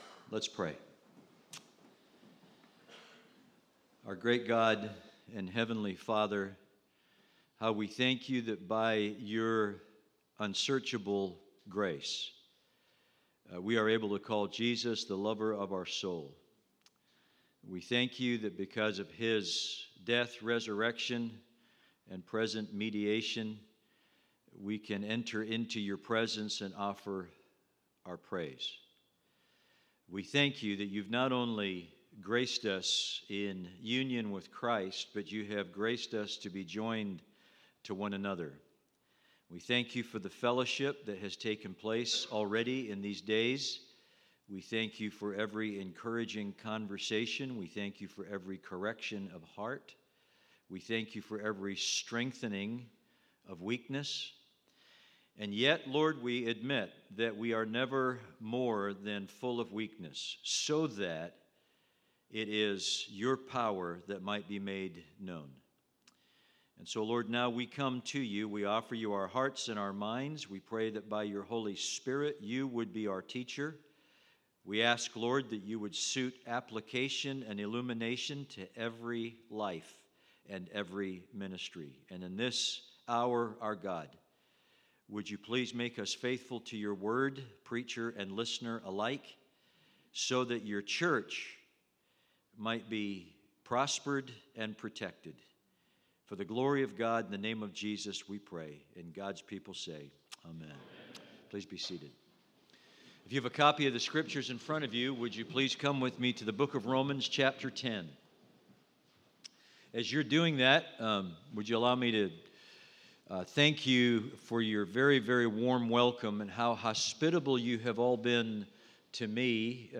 Worship Service 2: An Awakened Ministry is a Mission-Hearted Ministry